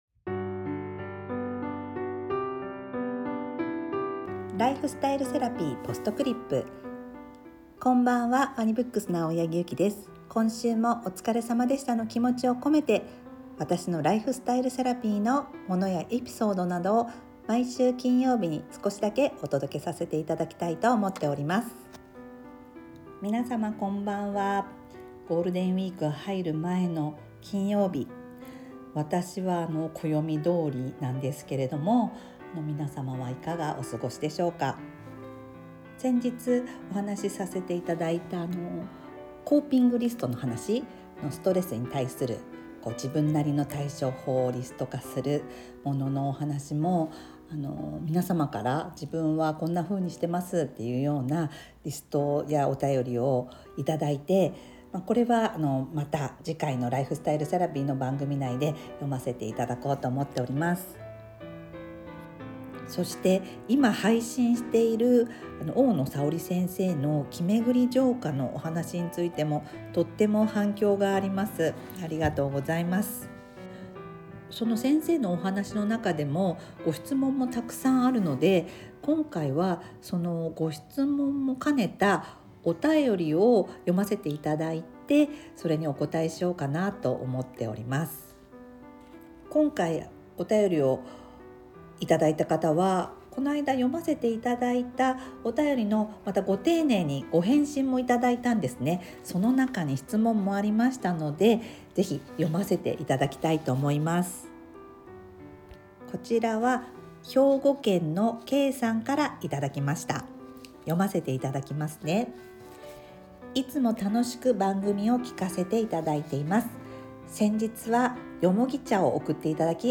BGM／MusMus